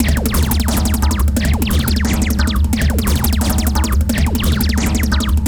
__SCRATX 1.wav